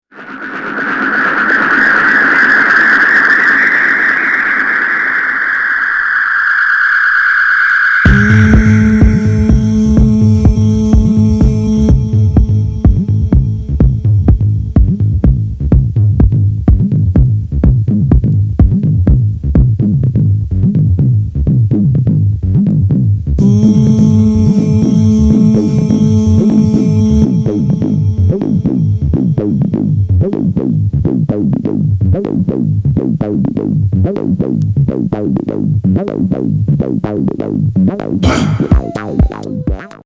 密林を抜けてから始まる、ディープ・ハウス〜グニョグニョのアシッド・
グルーブ〜テック・ハウス〜ディープ・レイブといろいろ姿を変える変幻自在